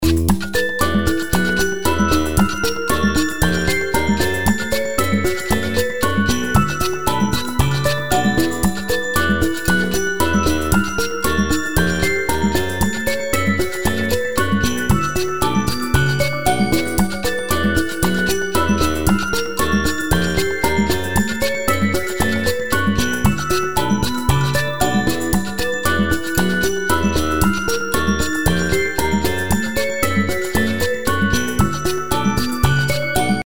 音楽ジャンル： ラテン
LOOP推奨： LOOP推奨
楽曲の曲調： SOFT